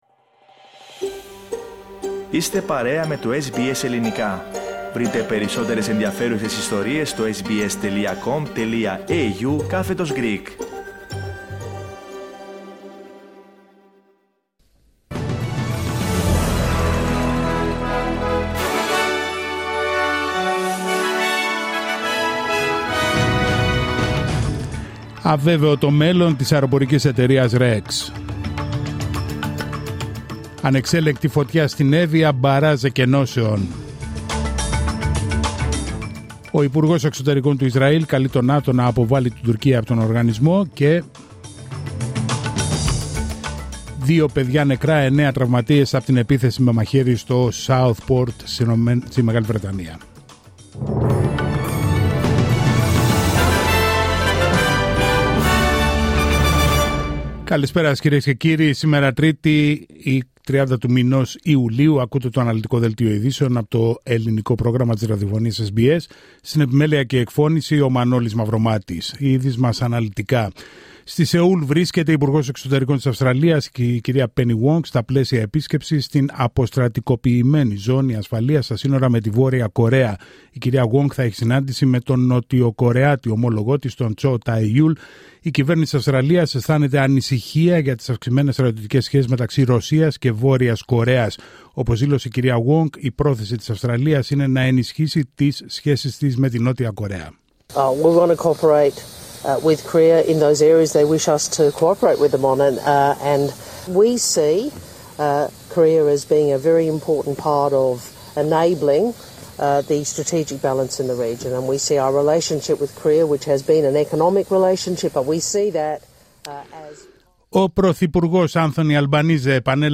Δελτίο ειδήσεων Τρίτη 30 Ιουλίου 2024